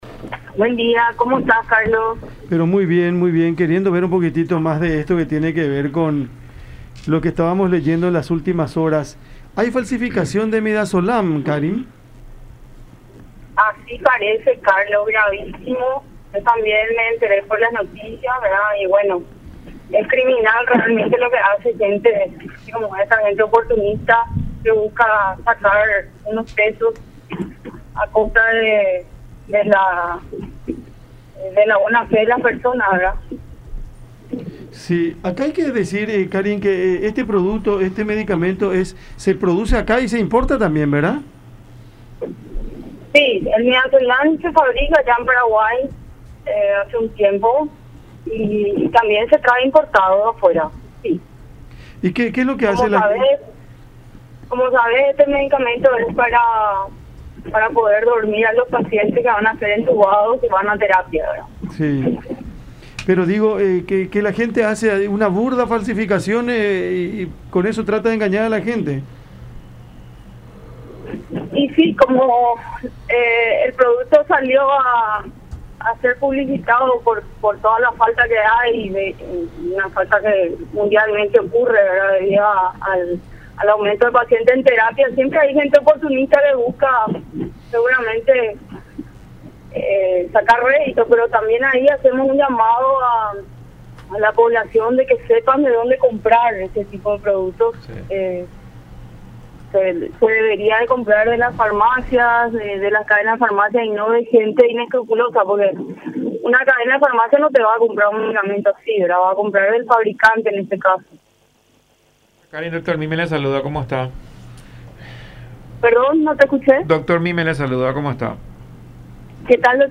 en diálogo con el programa Cada Mañana por La Unión.